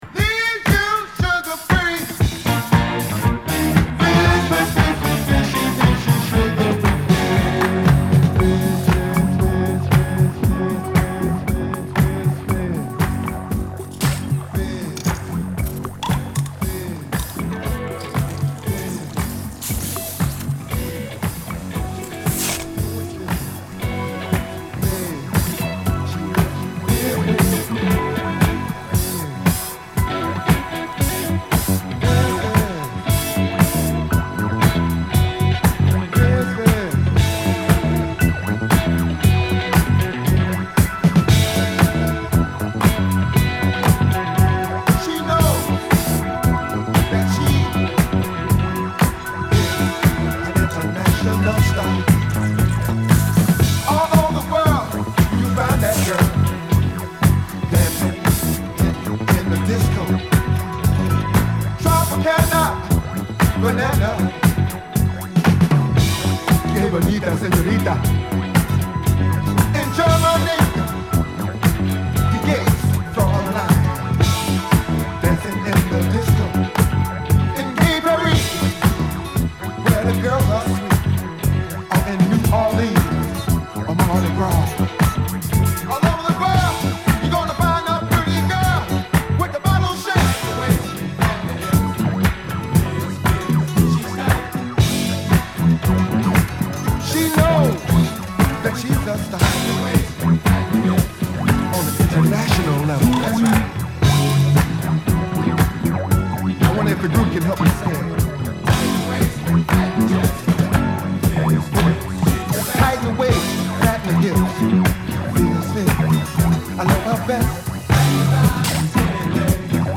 シグマスタジオ録音